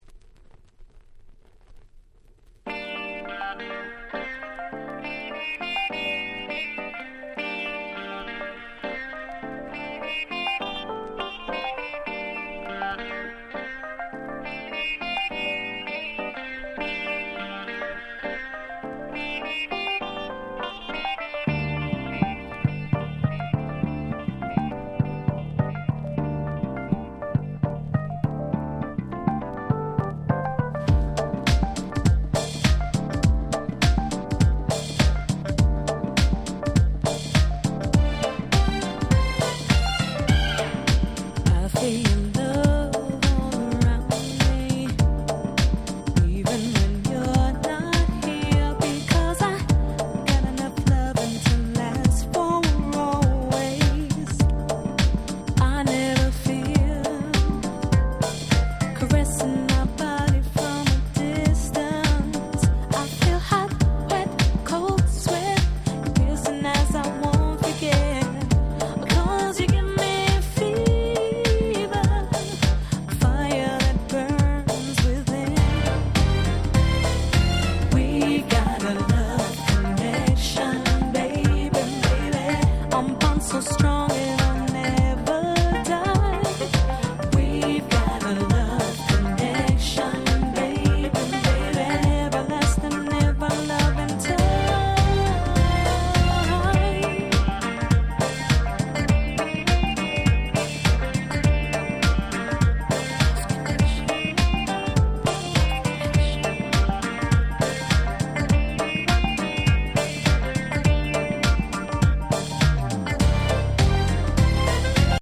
95' Super Nice R&B !!
本当に素敵な最高の歌モノです！！